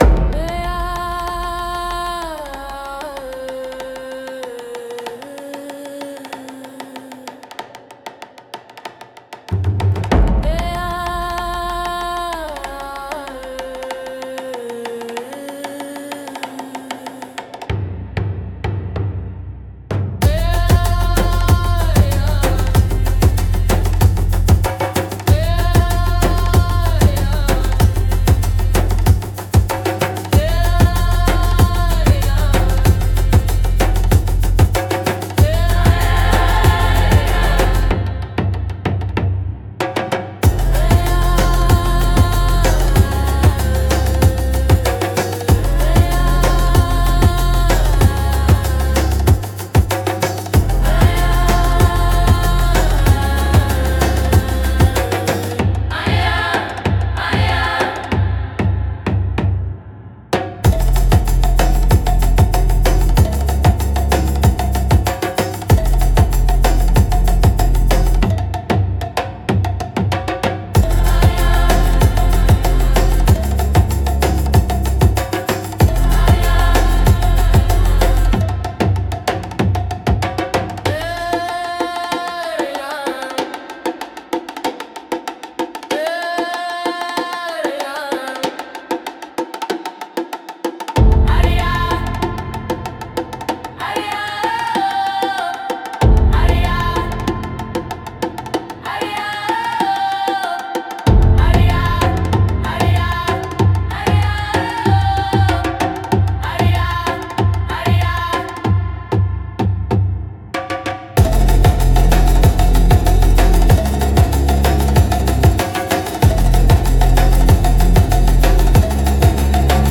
迫力と神秘性が共存するジャンルです。